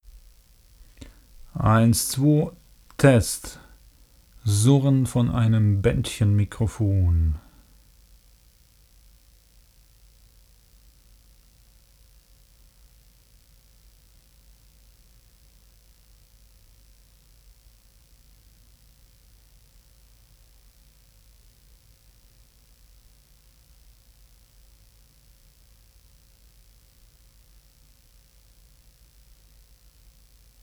Bändchenmikro NoHype LRM2b surrt
Nicht extrem laut, aber sehr deutlich hörbar. - Aufnahmegerät: MixPre3 - Mikro: NoHype LRM2b Was habe ich unternommen, um Fehlerquelle...